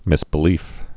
(mĭsbĭ-lēf)